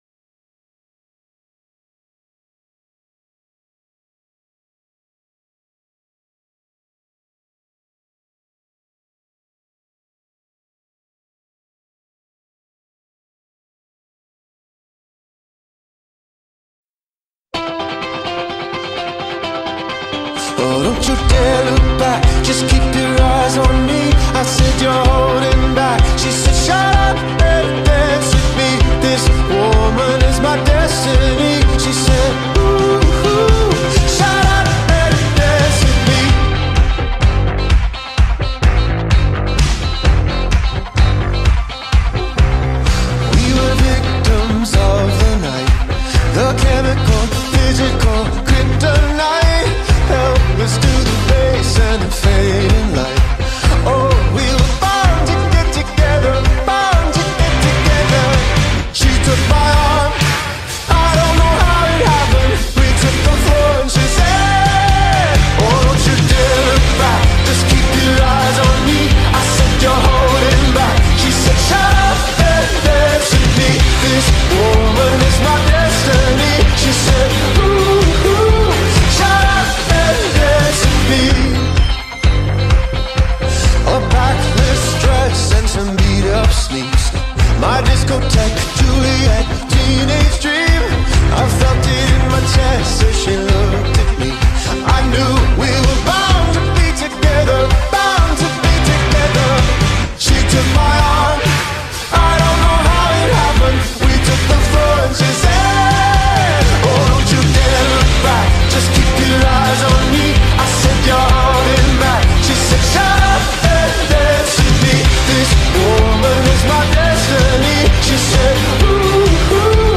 Backing track
• Lots of words but the same four chords!